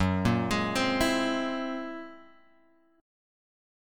F#9b5 chord {2 1 x 1 1 0} chord